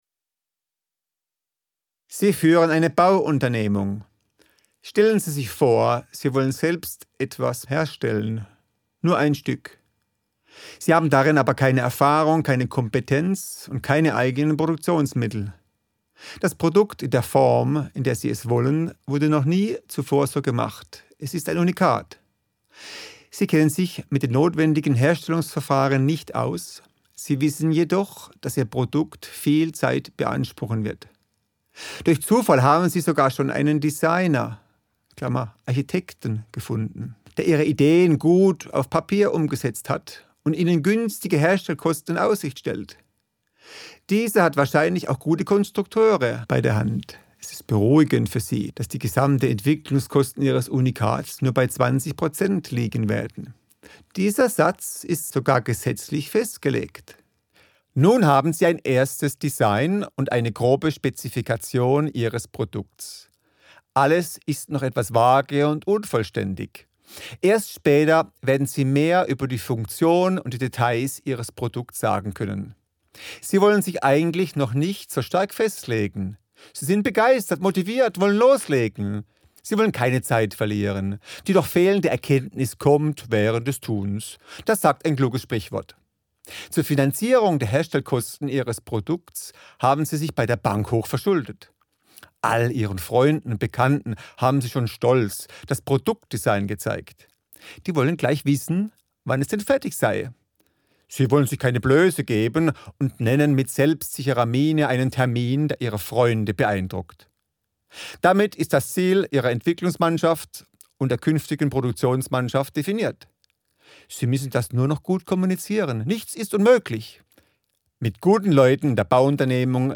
Auszug aus dem Hörbuch – Kapitel 1: